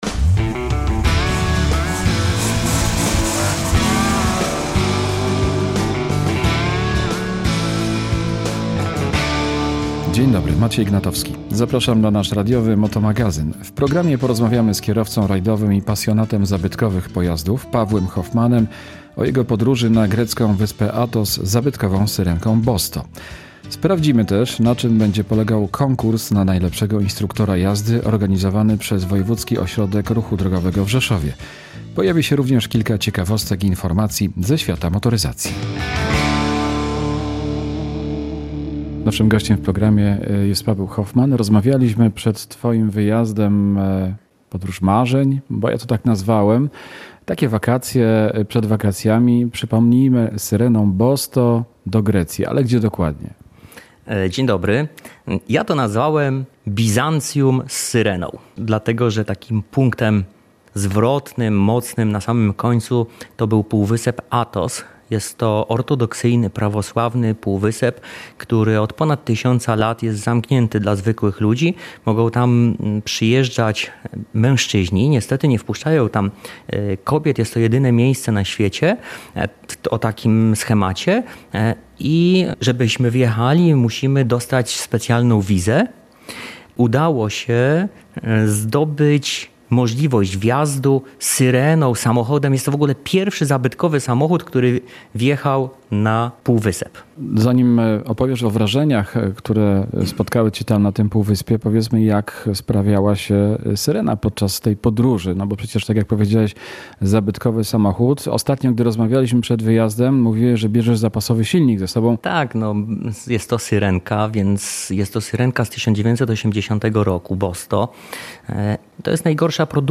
W programie rozmowa